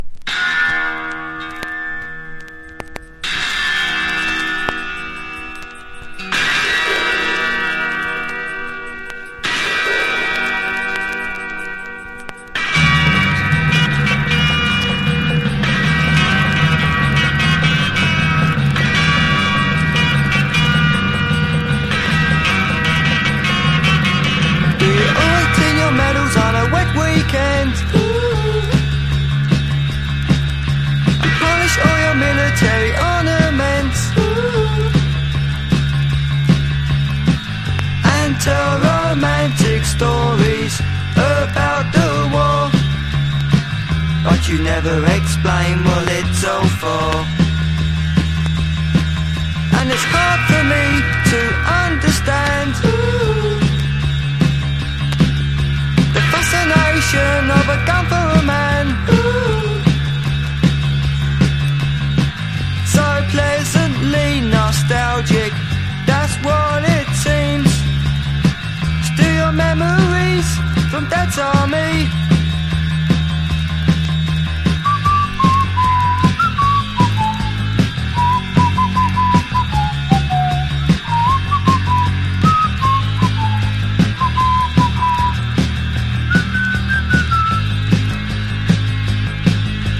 チープなサウンドが鳴り響くモッドかつネオ・アコースティックなギターポップ!!